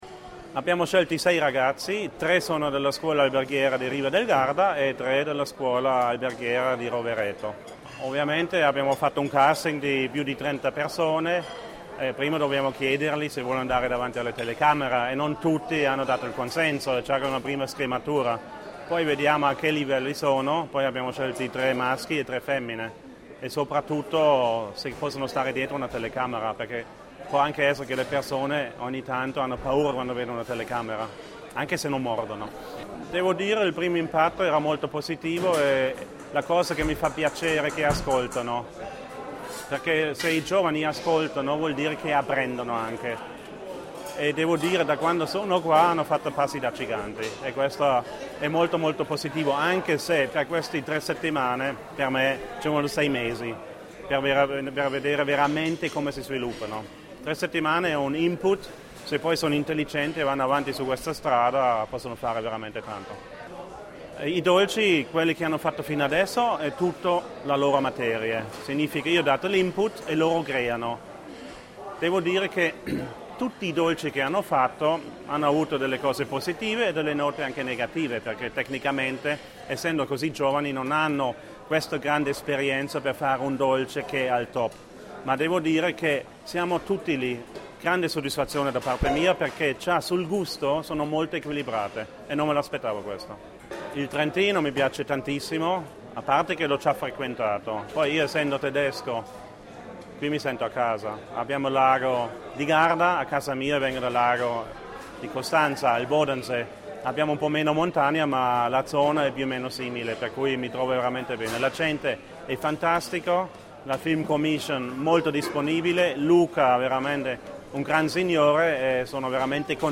intervista_re_del_cioccolato.mp3